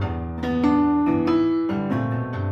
Index of /musicradar/gangster-sting-samples/95bpm Loops
GS_Piano_95-E1.wav